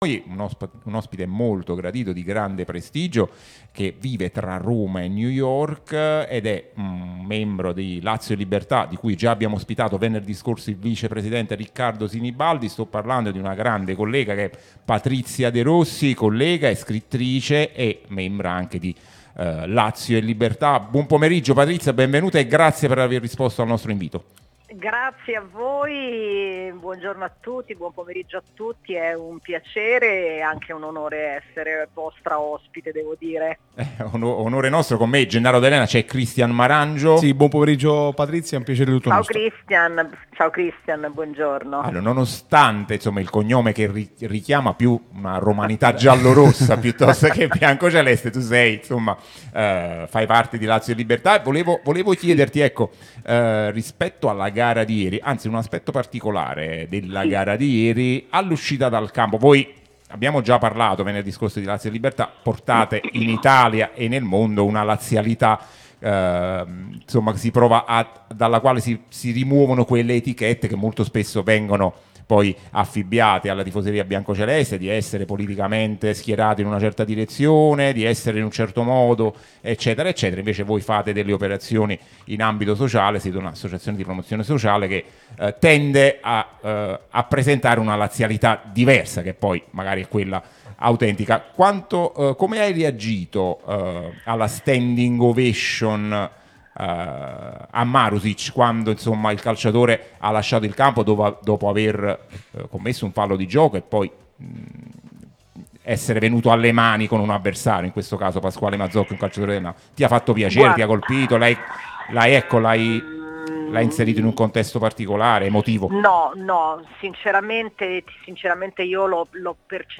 Radio TN